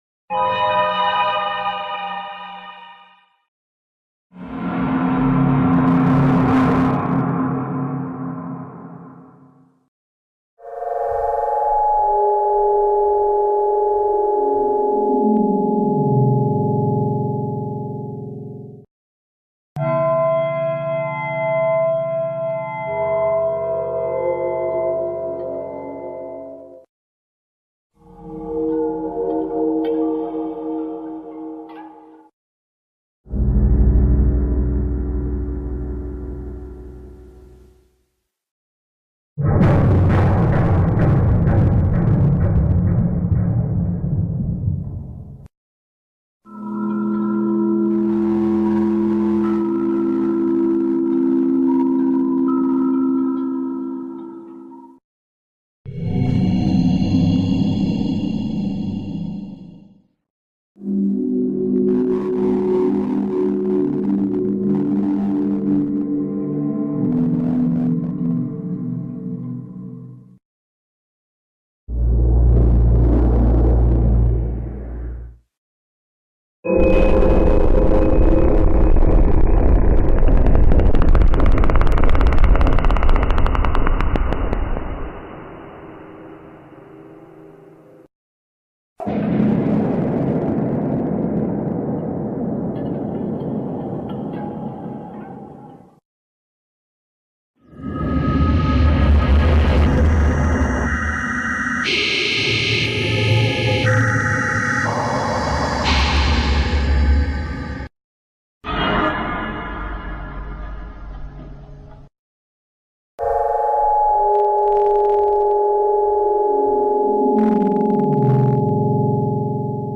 Creepy sounds but unsettling monsters sound effects free download